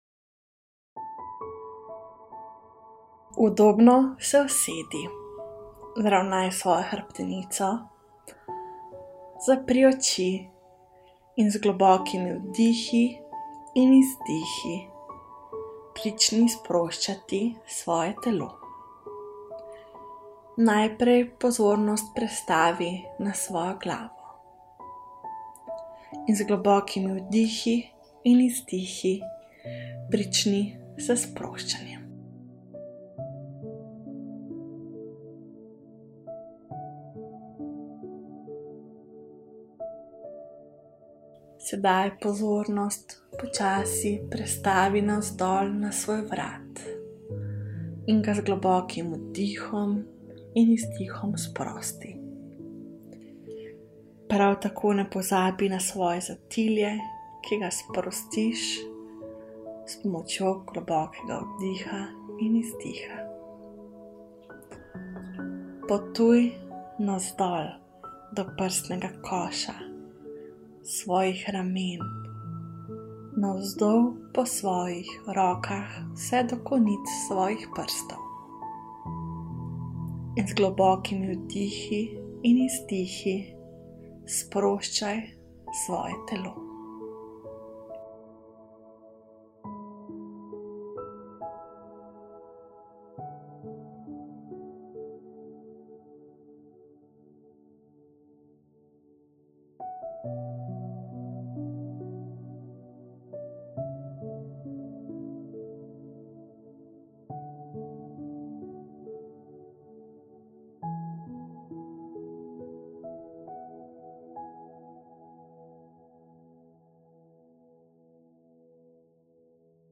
Meditacija za magnetizaciju